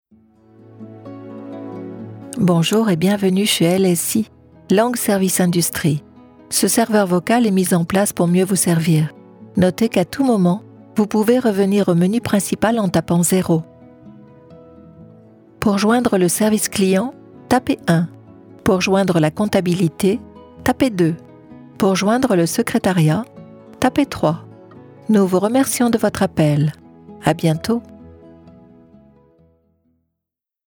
Voix off, comédienne française, voix femme adulte, medium, documentaire, institutionnel, audio guide, elearning
Sprechprobe: Sonstiges (Muttersprache):